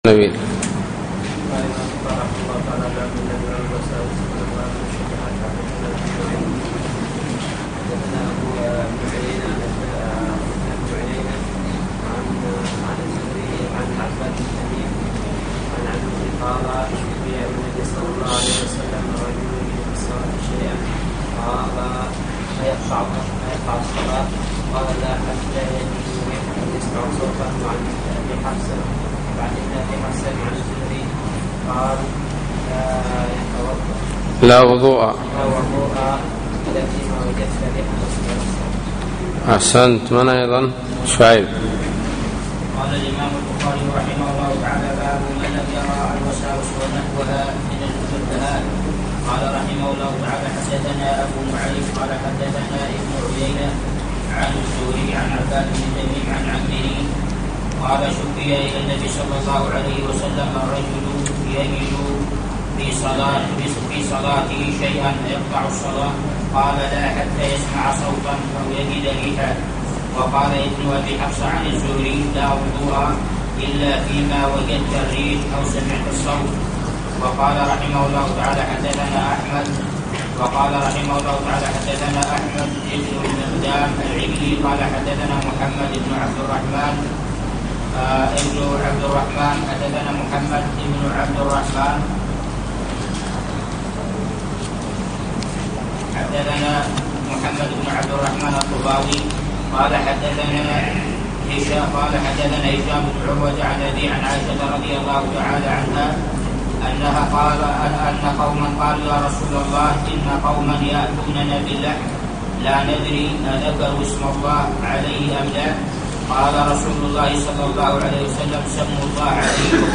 الدرس السابع : بَاب: قَوْلِ اللَّهِ تَعَالَى: وَإِذَا رَأَوْا تِجَارَةً أَوْ لَهْوًا انْفَضُّوا إِلَيْهَا، و بَاب: مَنْ لَمْ يُبَالِ مِنْ حَيْثُ كَسَبَ الْمَالَ